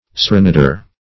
Serenader \Ser`e*nad"er\, n. One who serenades.